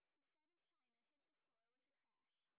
sp20_exhibition_snr30.wav